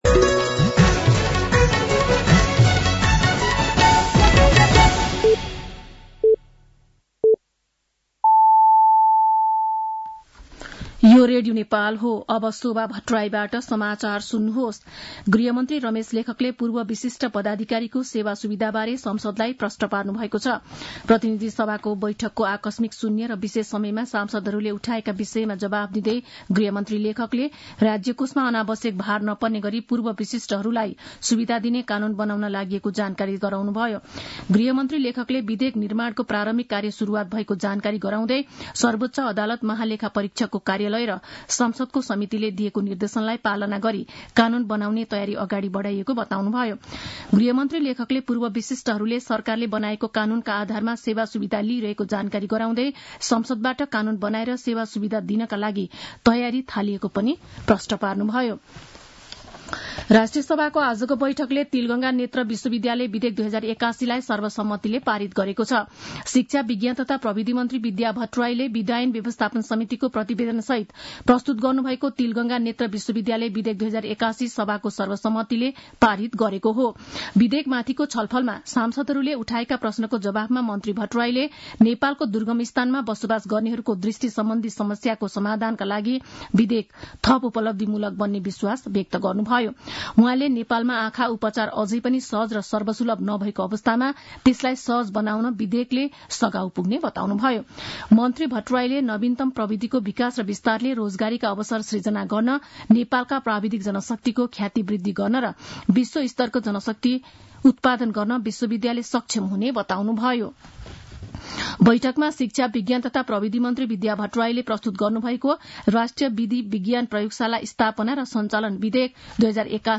साँझ ५ बजेको नेपाली समाचार : ४ चैत , २०८१
5-pm-news-6.mp3